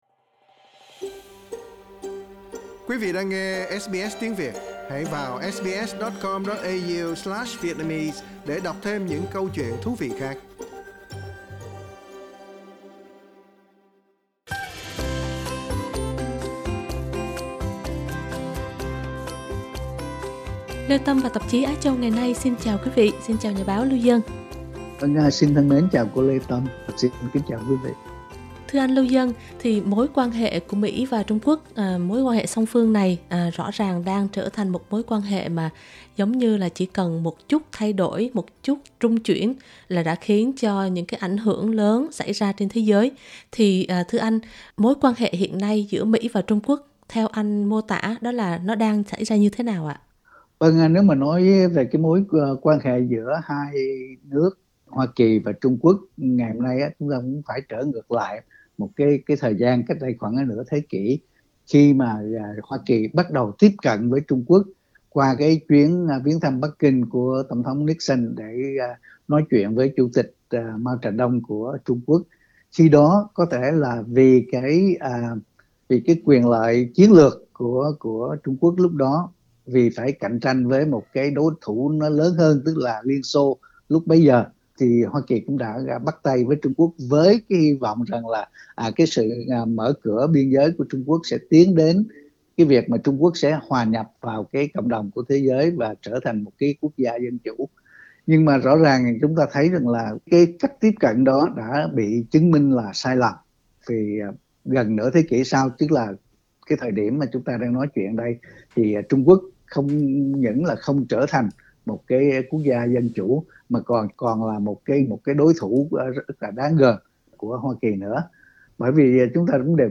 bài bình luận